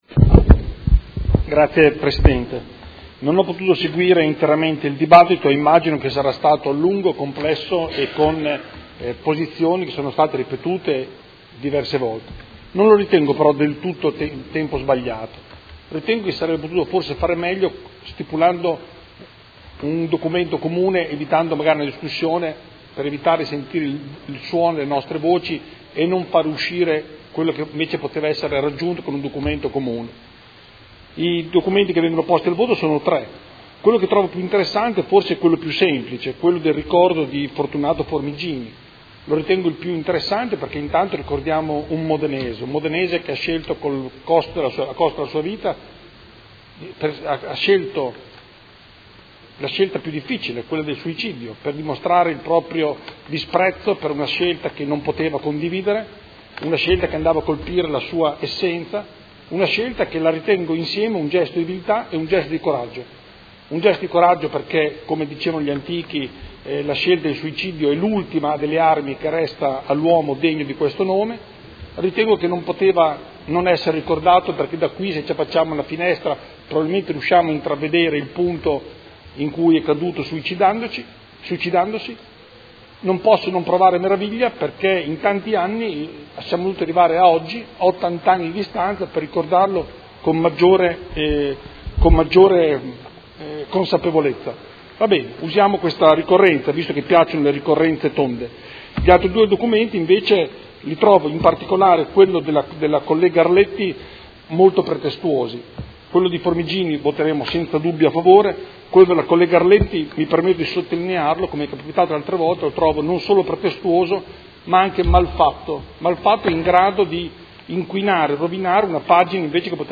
Seduta dell’8/11/2018.